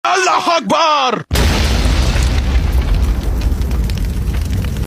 ALLAH AKBAR Chant Sound Effect
Experience the powerful 'ALLAH AKBAR' chant, a significant phrase in Islam.
allah-akbar-chant-sound-effect-f7e9641a.mp3